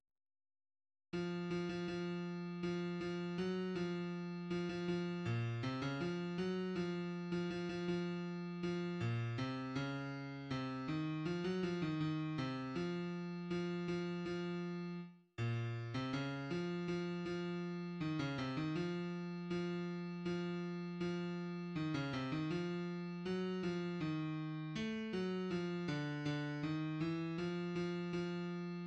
{ \clef bass \tempo 4=80 \key des \major \time 2/4 \set Score.currentBarNumber = #1 \bar "" r4 r8 f8 f16 f16 f4 f8 f ges f4 f16 f f8 bes, c16 des f8 ges f8. f16 f16 f16 f4 f8 bes, c des4 c8 ees f16 ges f ees ees8 c f4 f8 f f4 r8 bes,~ bes,16 c des8 f f f4 ees16 des c ees f4 f f f4 ees16 des c ees f4 ges8 f ees4 a8 ges f d8 d ees e f f f4 } \addlyrics {\set fontSize = #-2 doggy doogy } \midi{}